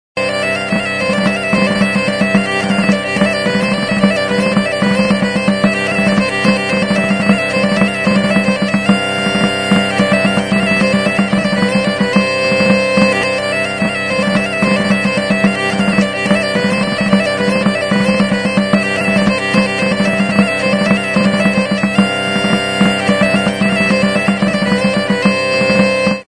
Stringed -> Bowed
Stringed -> Keyed